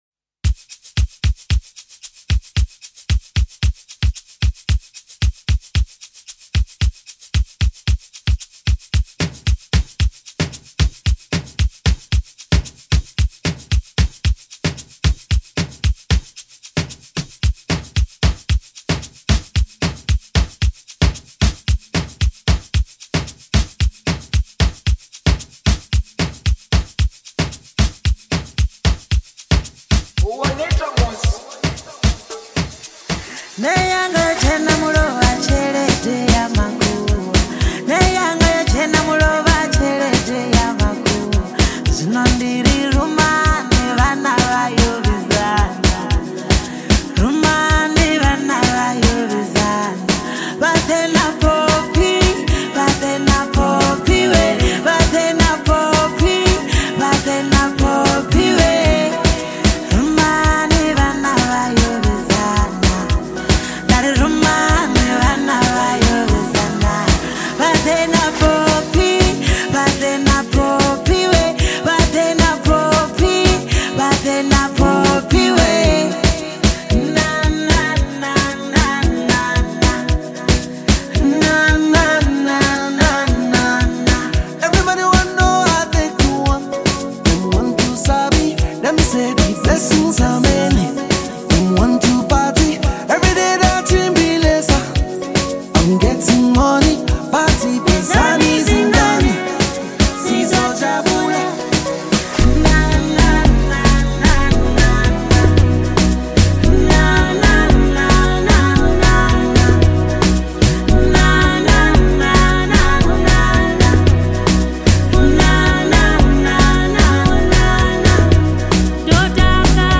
powerful vocals
soulful harmonies
smooth delivery